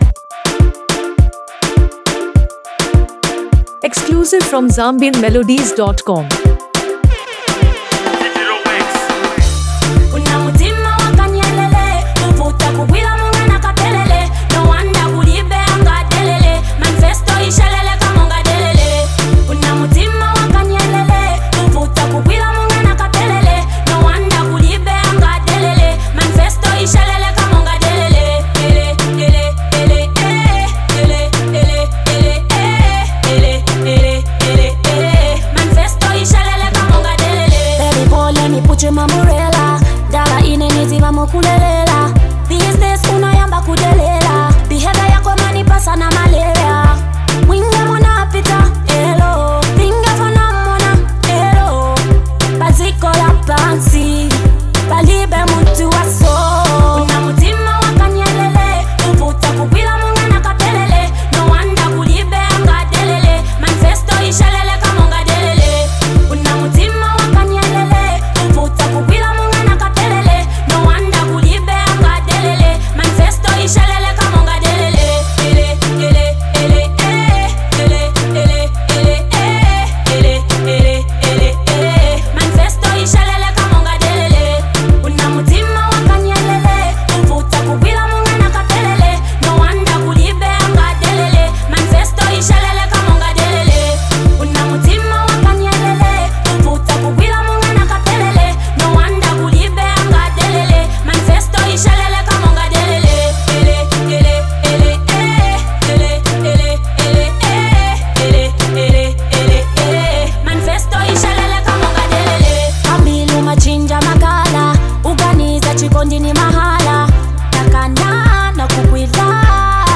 Belonging to the Afro-pop and Zambian contemporary genre